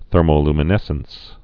(thûrmō-lmə-nĕsəns)